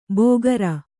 ♪ bōgara